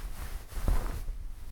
cloth.ogg